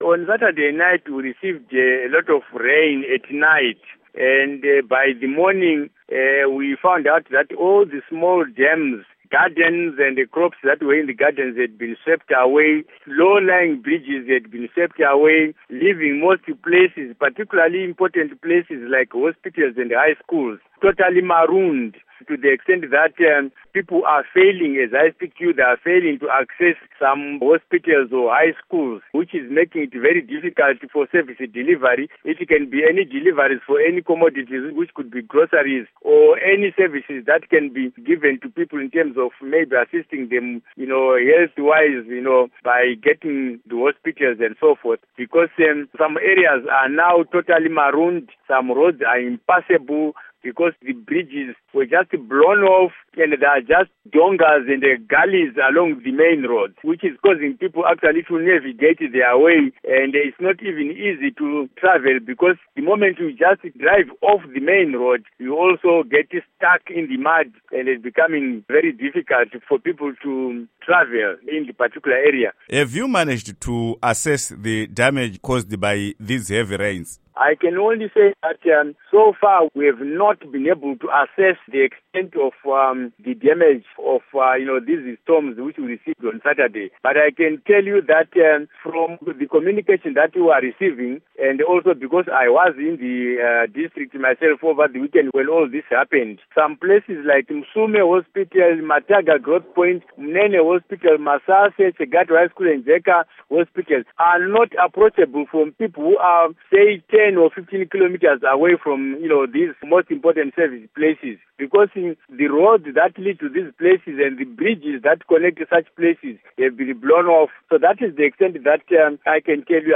Interview With Joram Gumbo